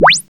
SI2 ZAPP.wav